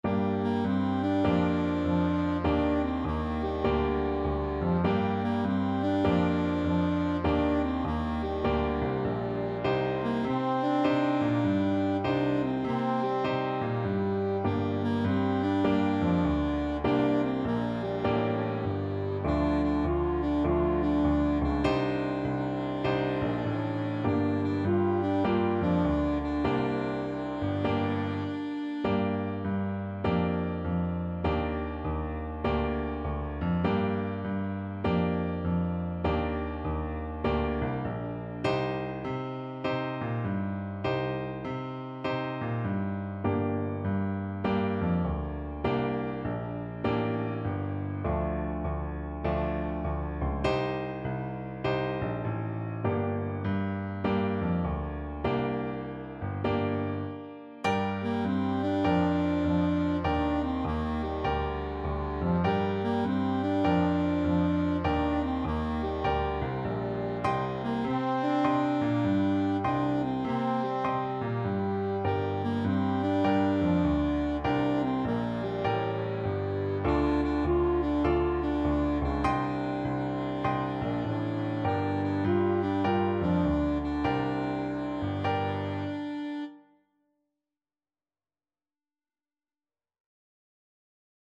Alto Saxophone
Trombone
A simple blues tune with an improvisatory section
With a swing!